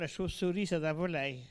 Localisation Soullans
Catégorie Locution